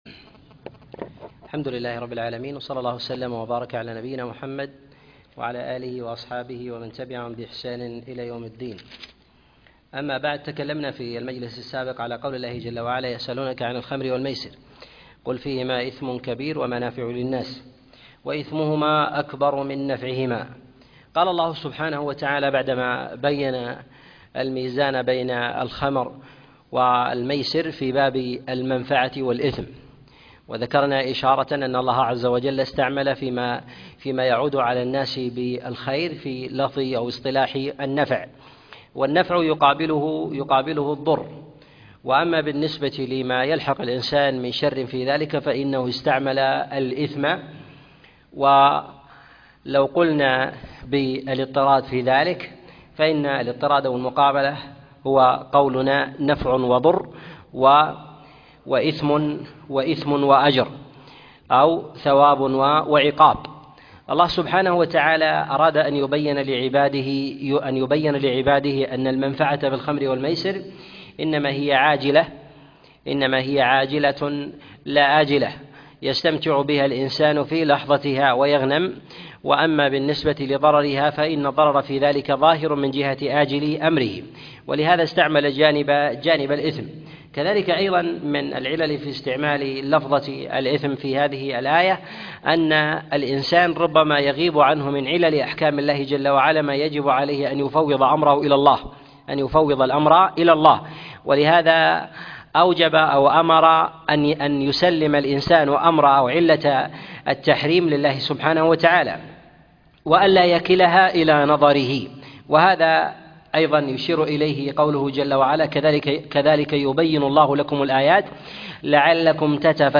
تفسير سورة البقرة 24 - تفسير آيات الأحكام - الدرس الرابع والعشرون